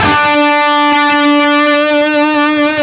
LEAD D 3 CUT.wav